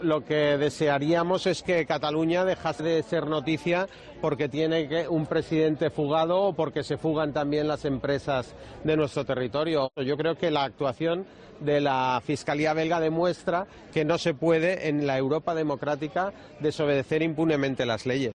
"Dicen que lo hacen para defender nuestras instituciones. Es mentira, nuestras instituciones las han cargado ustedes", ha reprochado el dirigente de la formación naranja durante el pleno convocado en la cámara catalana para responder a la aplicación del artículo 155 de la Constitución en Cataluña.